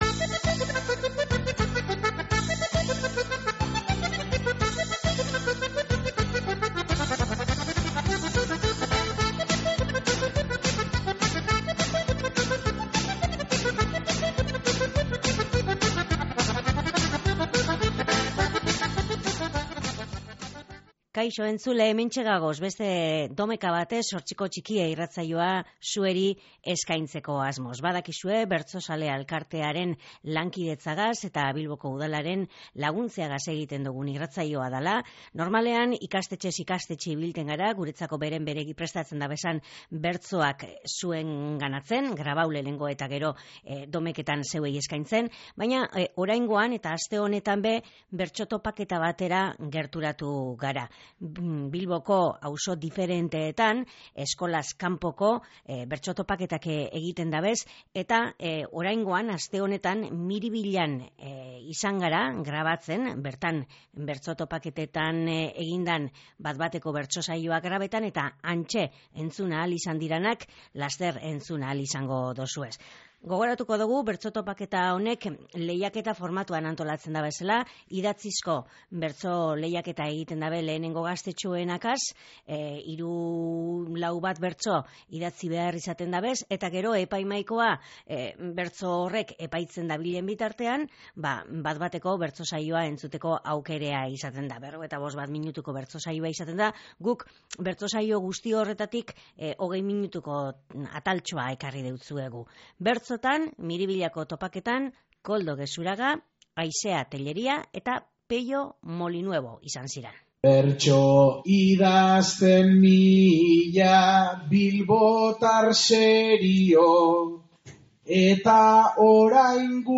Idatzizko bertso txapelketa eta bat-bateko saioa izan dira topaketan